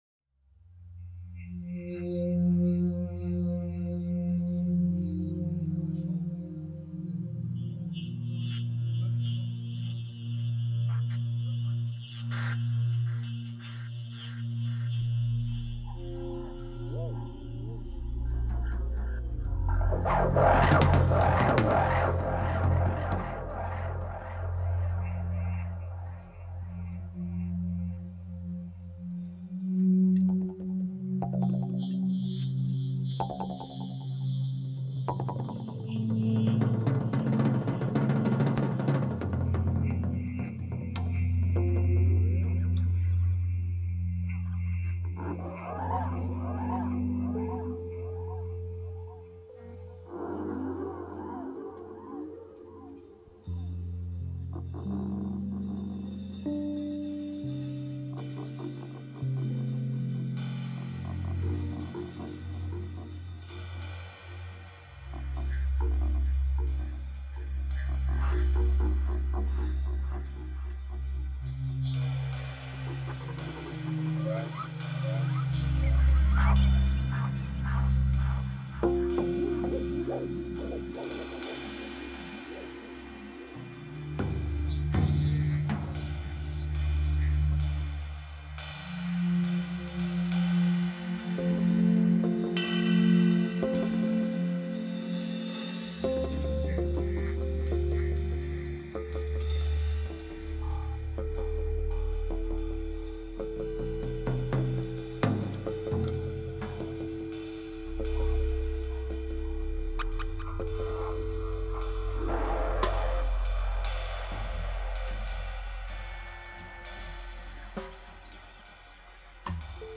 Genres: fusion , improv , jazz , latvia , rock